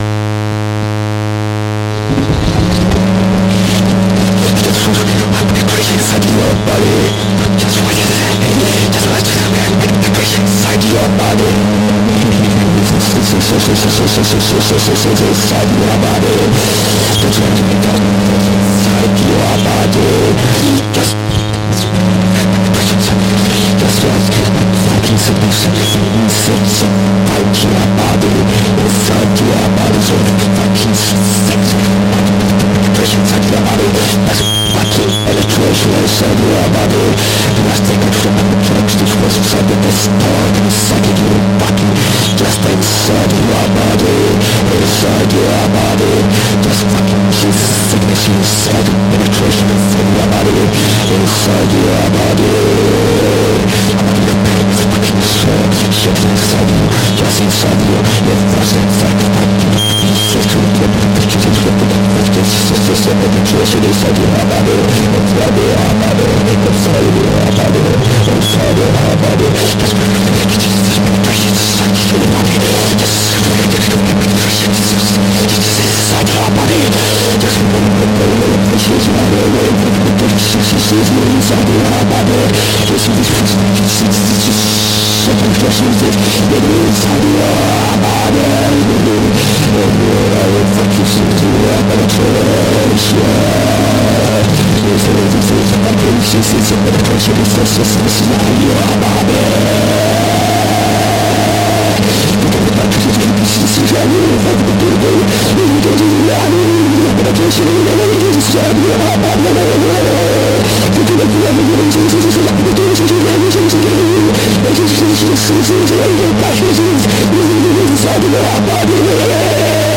high-pitched drones
• Genre: Death Industrial / Power Electronics